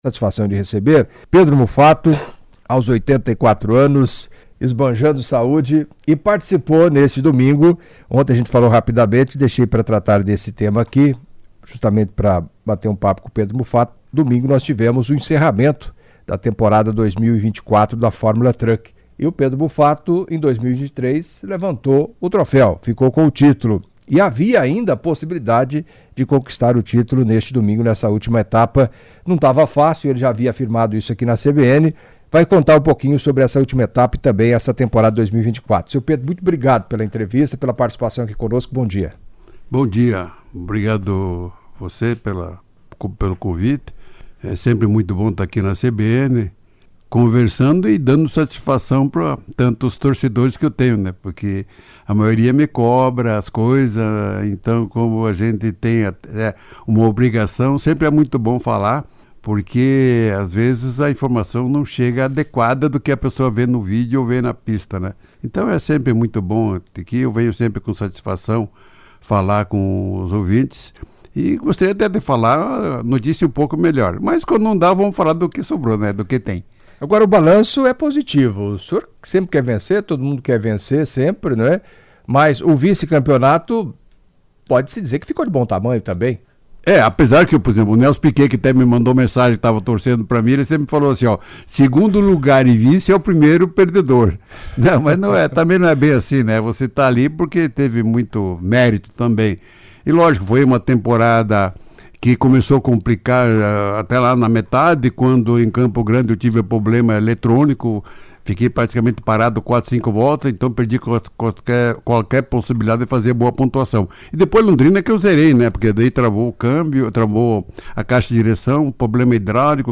Em entrevista à CBN nesta terça-feira (03) Pedro Muffato, 84 anos, empresário, ex-prefeito de Cascavel, piloto da Fórmula Truck, apresentou um balanço da sua atuação nas pistas nessa temporada de 2024; falou da alimentação e como se prepara para as corridas; relatou parte de sua história e contou alguns detalhes da vida do irmão e das três irmãs (os quatro já falecidos); respondeu dúvidas de ouvintes e agradeceu às inúmeras mensagens recebidas de admiradores, que acompanhavam a entrevista.